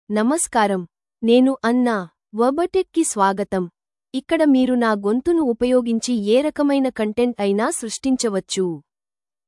Anna — Female Telugu AI voice
Anna is a female AI voice for Telugu (India).
Voice sample
Listen to Anna's female Telugu voice.
Female
Anna delivers clear pronunciation with authentic India Telugu intonation, making your content sound professionally produced.